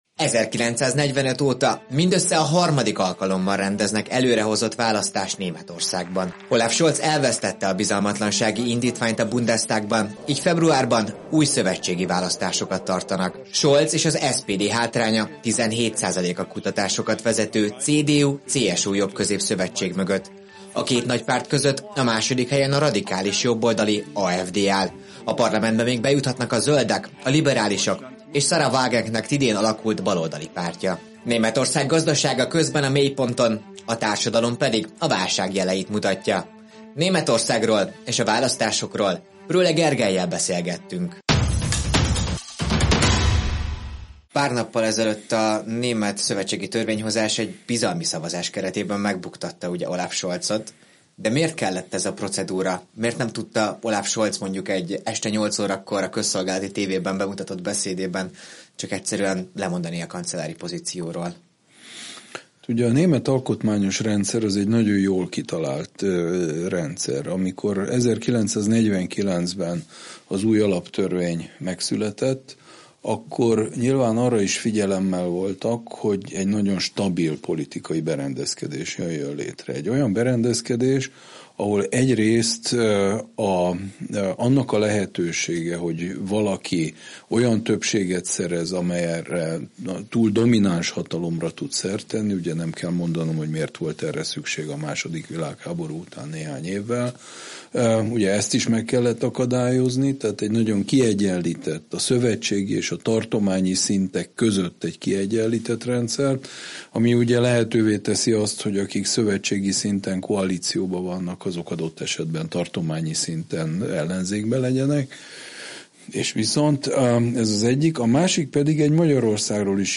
Olaf Scholz elvesztette a bizalmatlansági indítványt a Bundestagban, így februárban új szövetségi választásokat tartanak Németországban. Prőhle Gergellyel beszélgettünk a voksolásról.